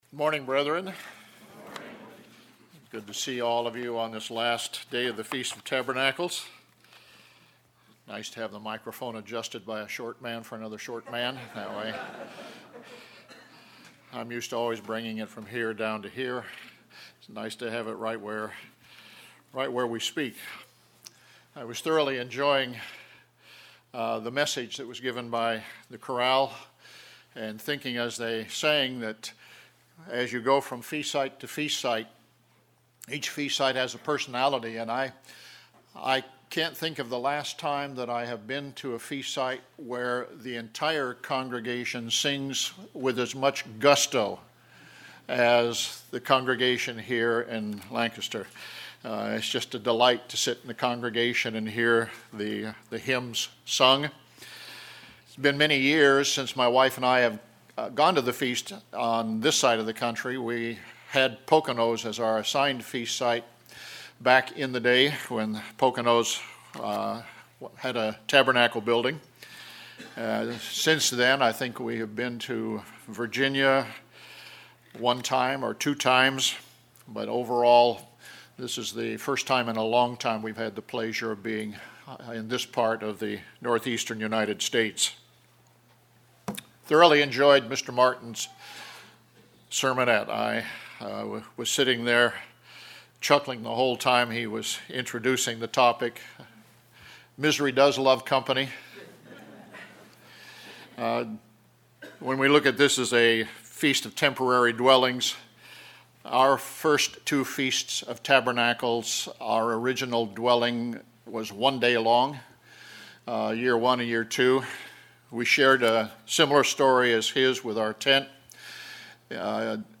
This sermon was given at the Lancaster, Pennsylvania 2019 Feast site.